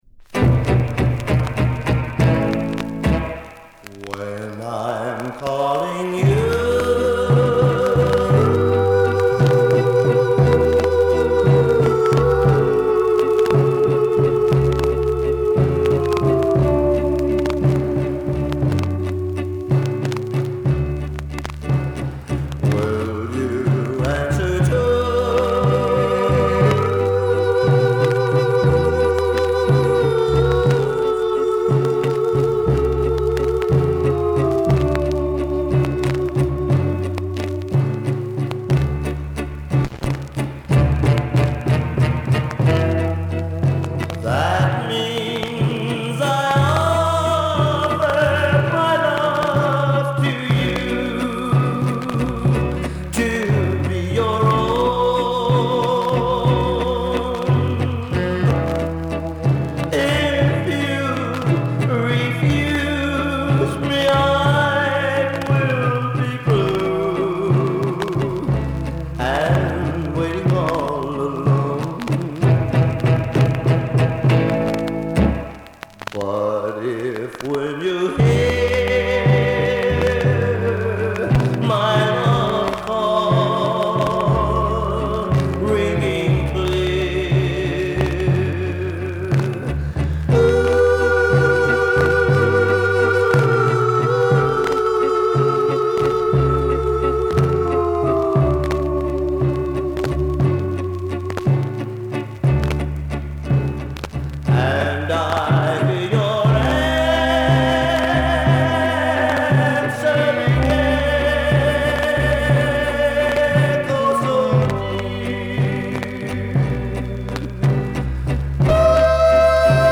大袈裟に歌い上げるヴォーカルが目立つバラード。
B面1/4くらい目立つスクラッチあり（画像）でバックグラウンドノイズはいります。